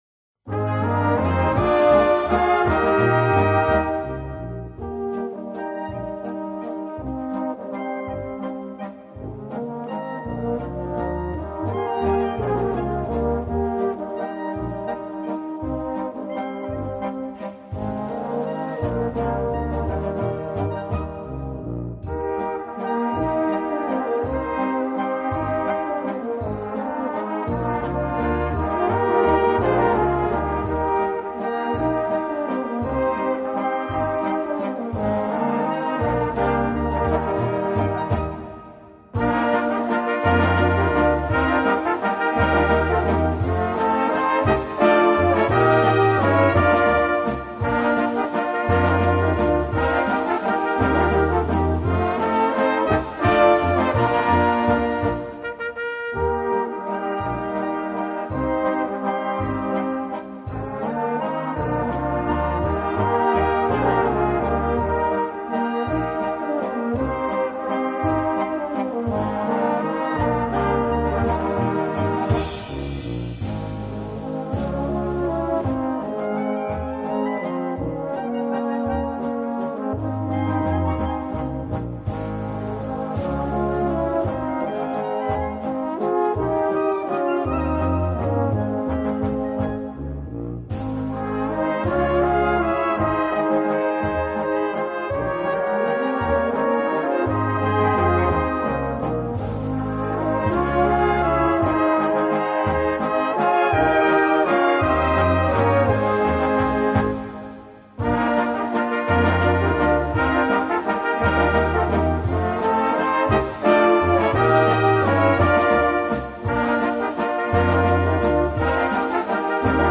Gattung: Walzer für kleine Besetzung
Besetzung: Kleine Blasmusik-Besetzung
Wunderschöner Walzer